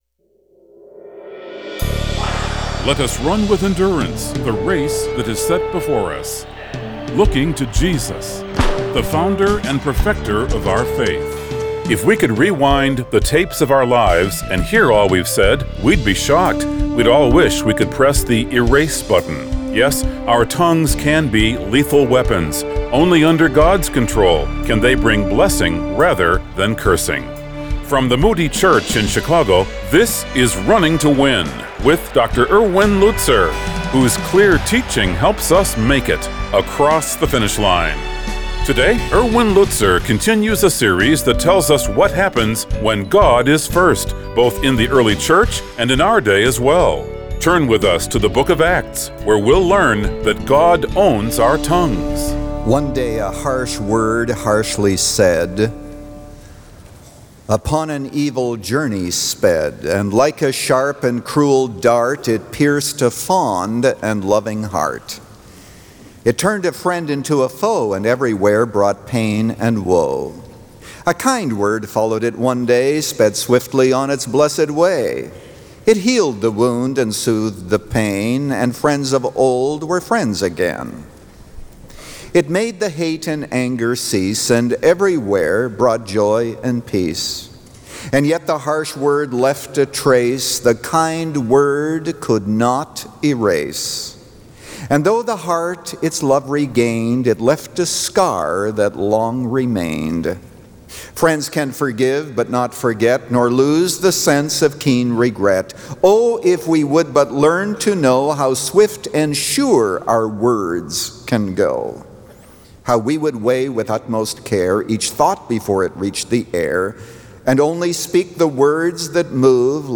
In this message from Acts 2, Pastor Lutzer shares key observations on the Holy Spirit’s gift of tongues. Discover why the tongue is such a powerful tool for God’s kingdom.
But with the Bible front and center and a heart to encourage, Pastor Erwin Lutzer presents clear Bible teaching, helping you make it across the finish line.